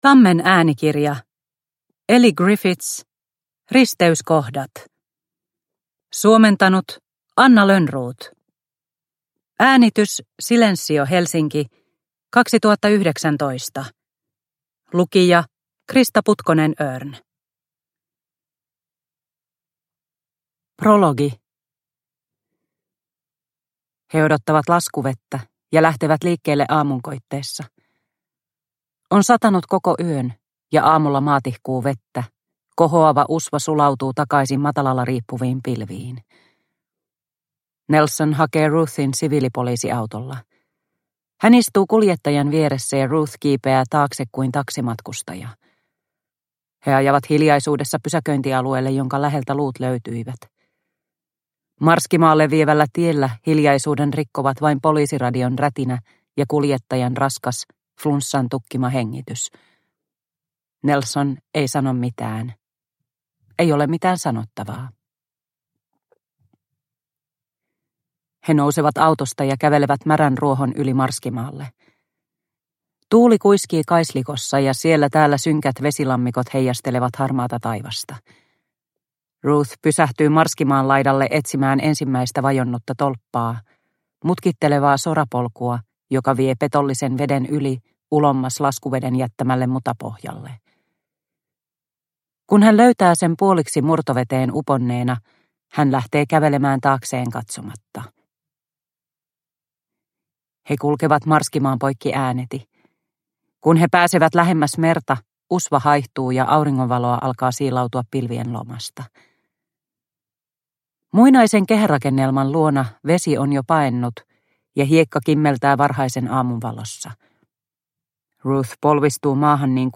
Risteyskohdat – Ljudbok – Laddas ner